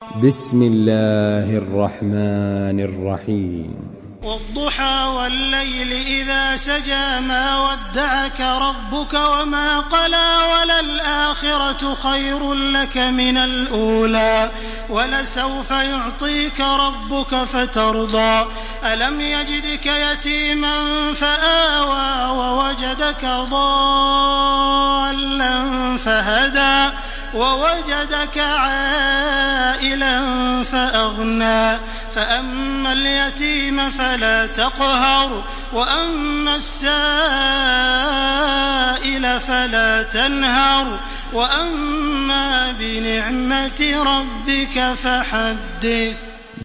Surah আদ্ব-দ্বুহা MP3 by Makkah Taraweeh 1429 in Hafs An Asim narration.
Murattal